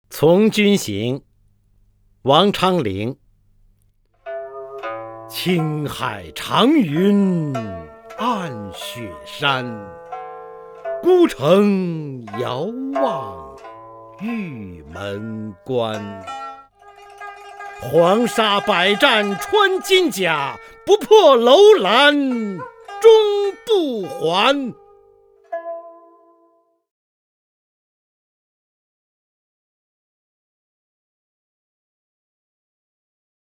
方明朗诵：《古从军行七首·其四》(（唐）王昌龄)
名家朗诵欣赏 方明 目录